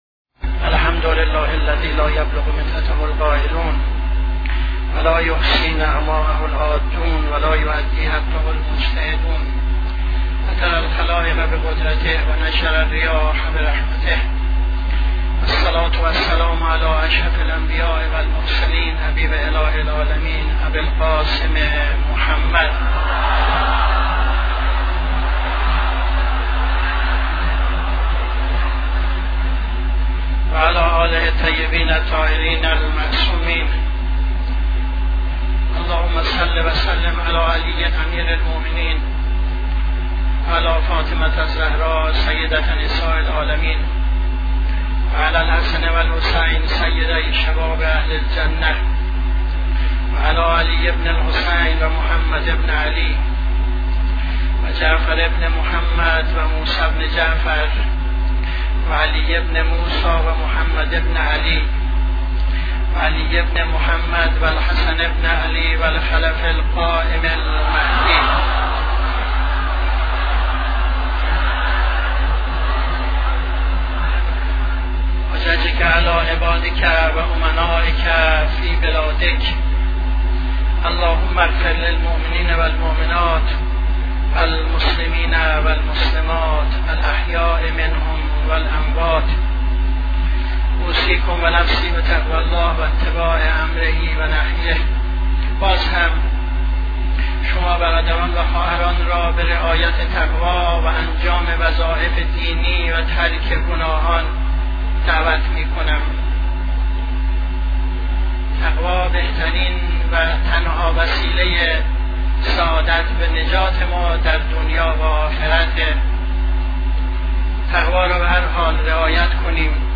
خطبه دوم نماز جمعه 07-06-76